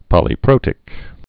(pŏlē-prōtĭk)